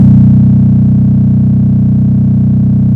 SICK BASS 7.wav